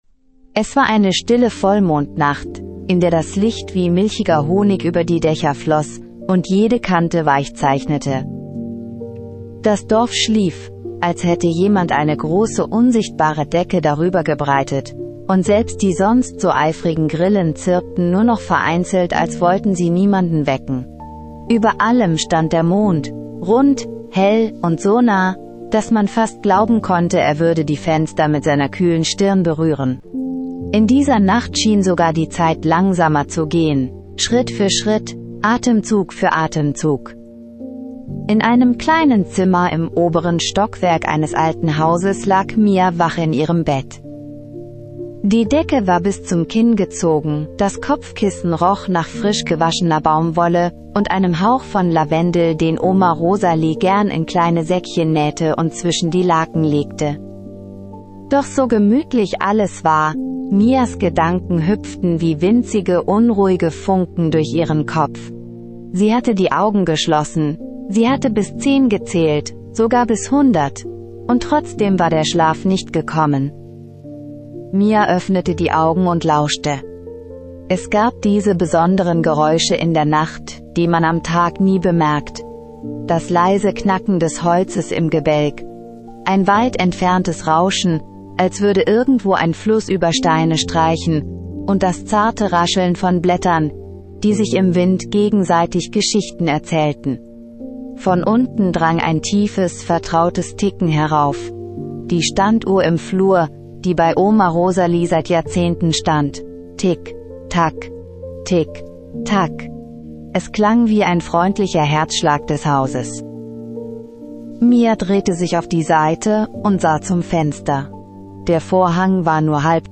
Mia entdeckt in einer Vollmondnacht einen geheimnisvollen silbernen Pfad und begegnet Lunaris, dem sanften Monddrachen. Eine magische Einschlafgeschichte für Kinder von 8-12 Jahren.